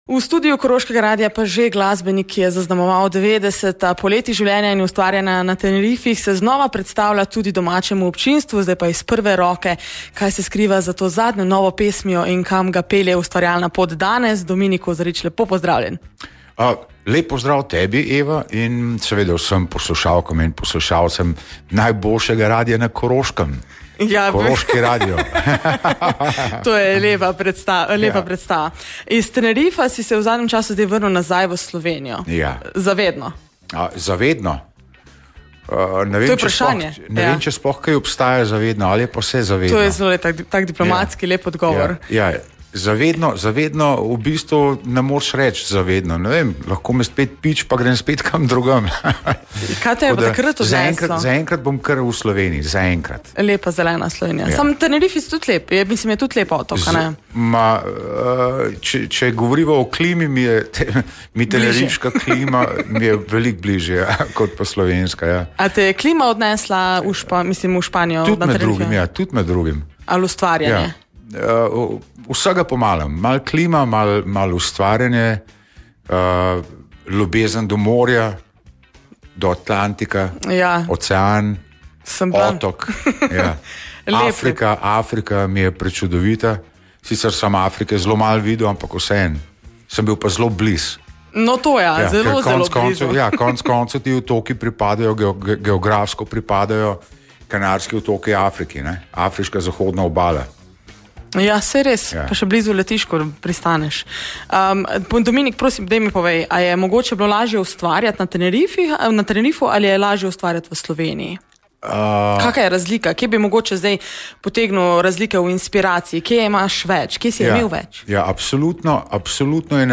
obisk v studiu